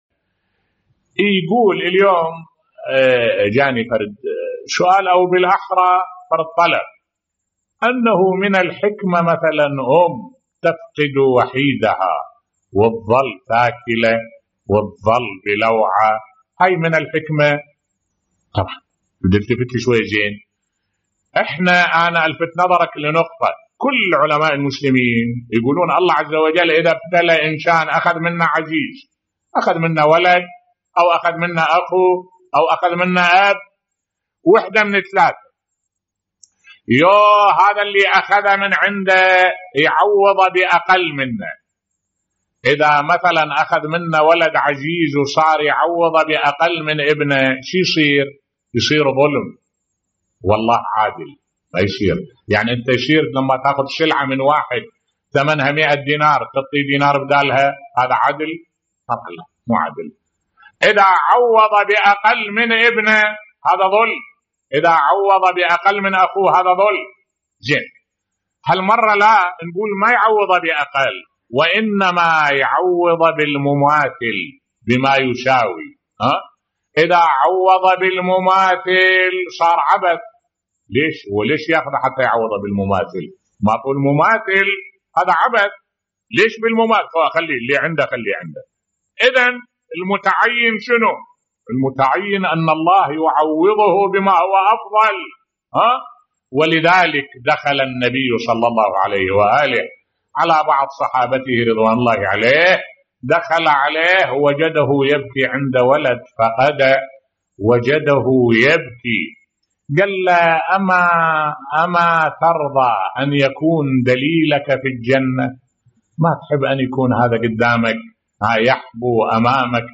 ملف صوتی فقد الام لابنها لا يتنافى مع حكمة الله بصوت الشيخ الدكتور أحمد الوائلي